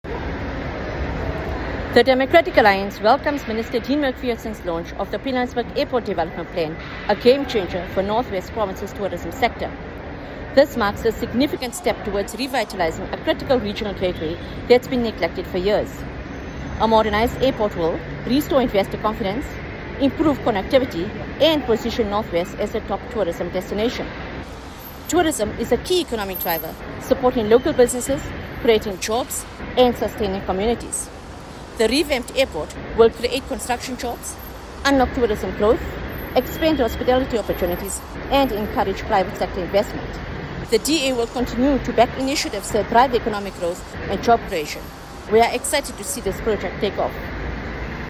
soundbite by Haseena Ismail MP.